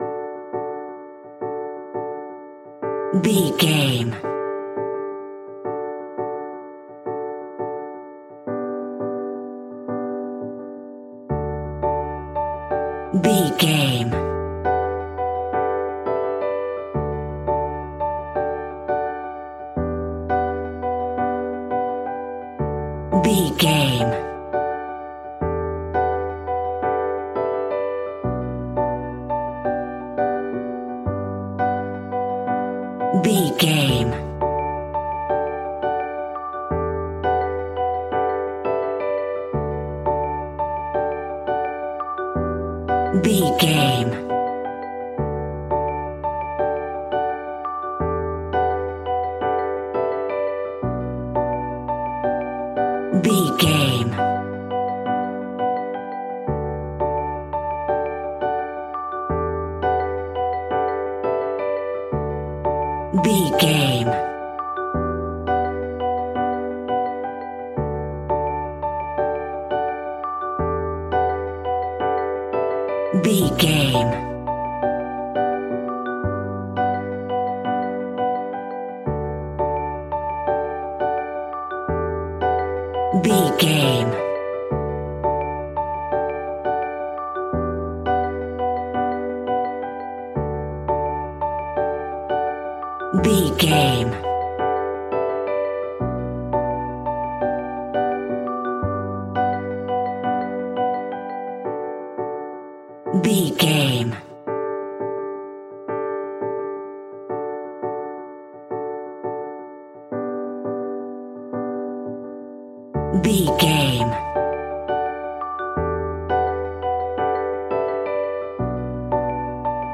Ionian/Major
D
energetic
uplifting
instrumentals
indie pop rock music
upbeat
groovy
guitars
bass
drums
piano
organ